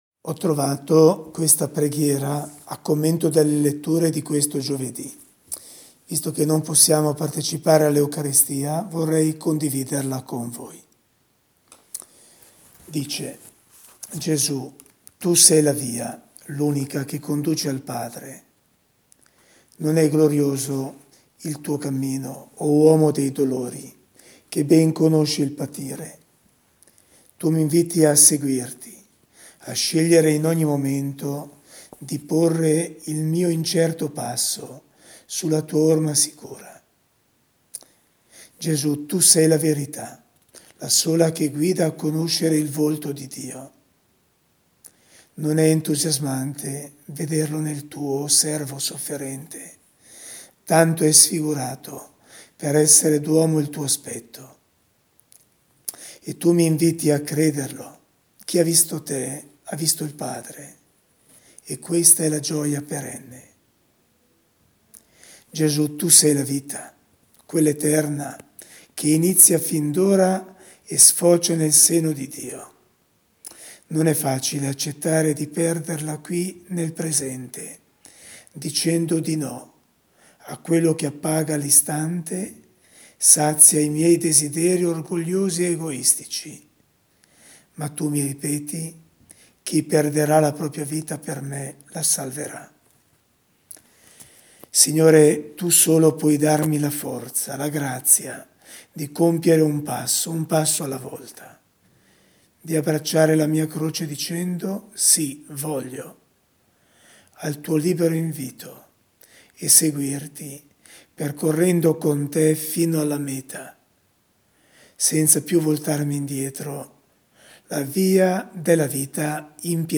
una riflessione in voce, tratta dalla Parola di Dio di oggi